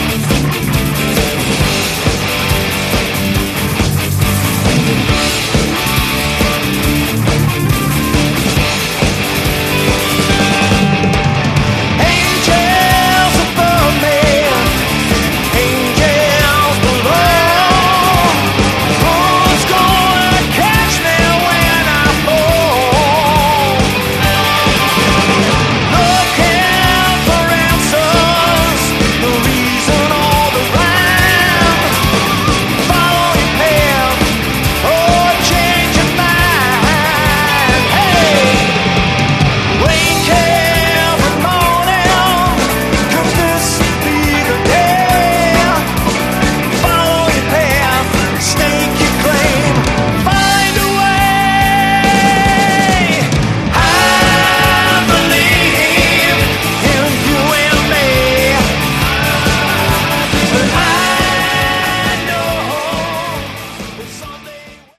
Category: AOR
drums, vocals
guitars, vocals
lead vocals
bass
guitar, vocals, keys